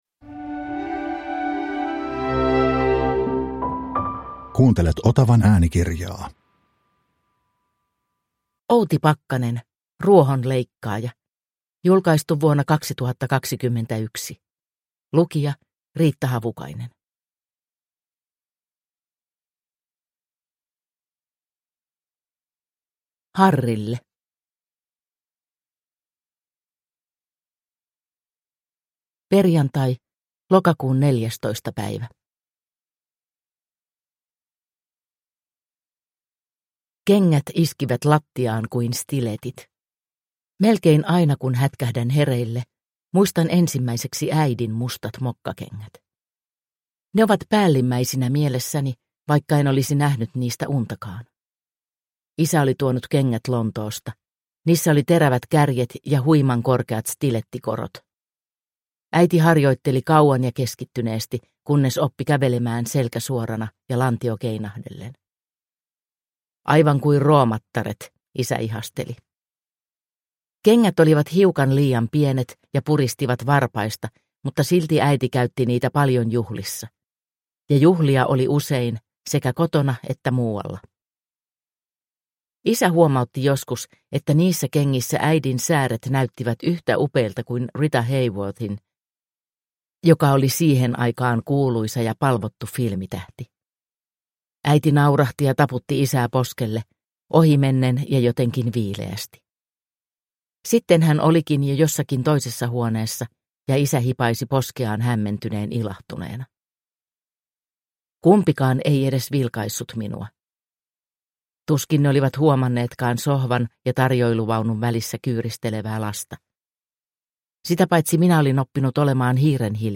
Ruohonleikkaaja – Ljudbok – Laddas ner
Uppläsare: Riitta Havukainen